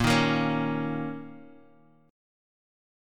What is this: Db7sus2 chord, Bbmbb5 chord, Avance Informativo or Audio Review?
Bbmbb5 chord